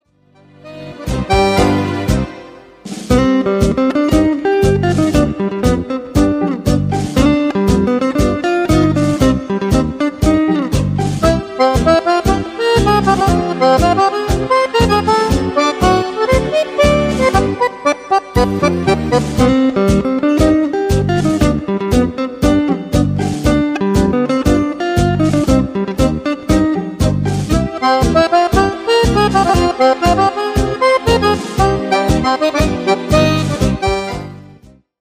TANGO  (3.20)